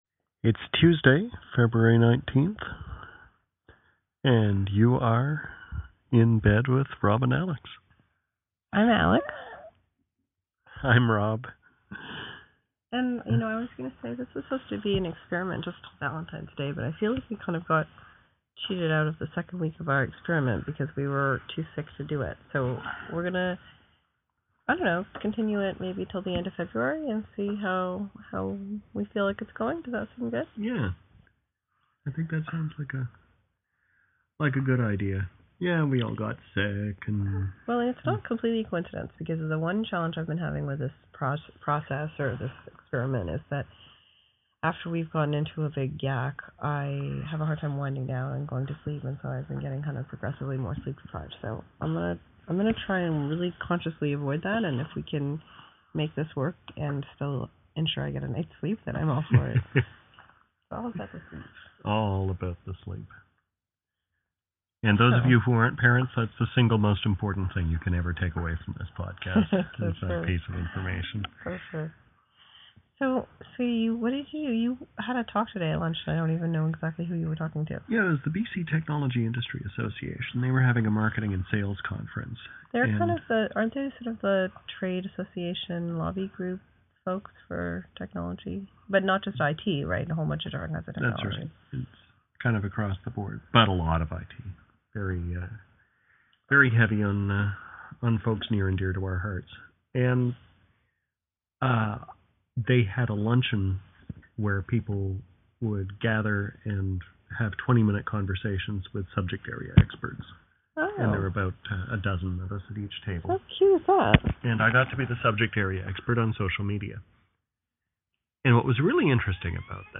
(Incidentally, you'll notice a few whining noises during this episode.